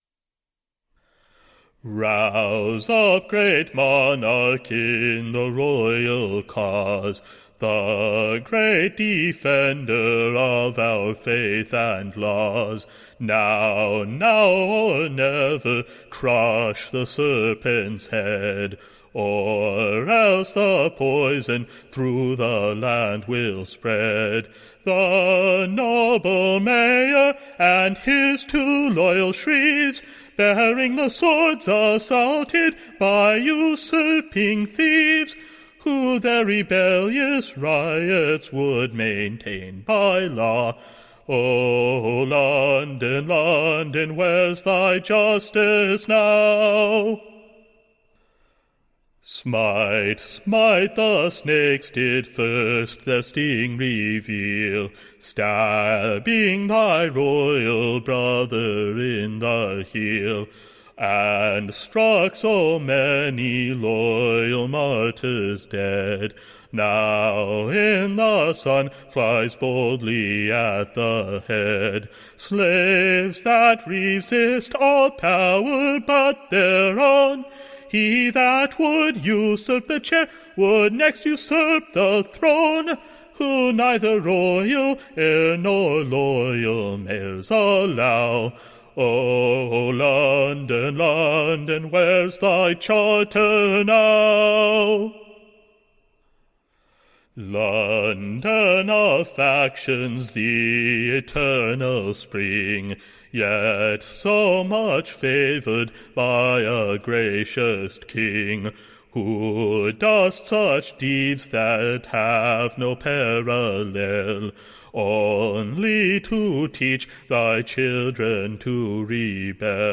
A Chant upon the Arresting the Loyal L. Mayor & Sheriffs
Tune Imprint To the Tune of, Burton Hall, or London's Loyalty.